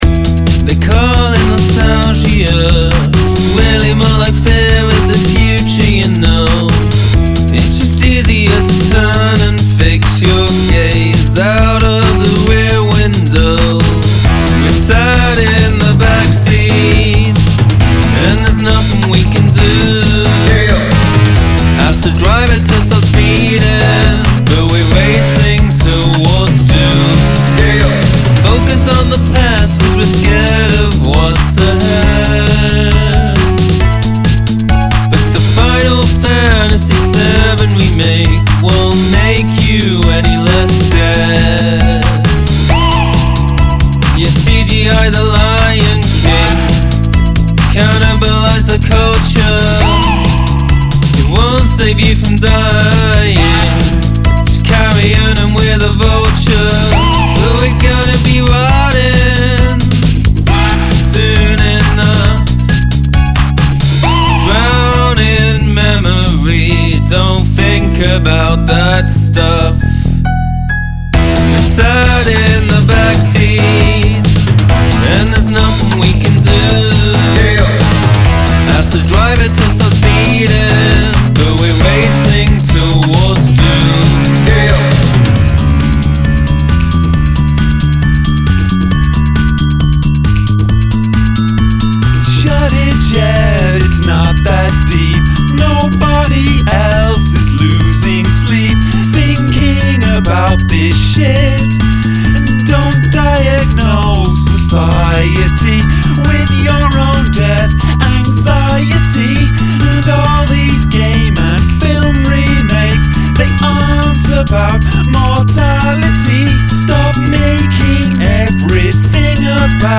Speeding things up at the end was an interesting choice.
The high end is crispy, and somewhat harsh on the ear.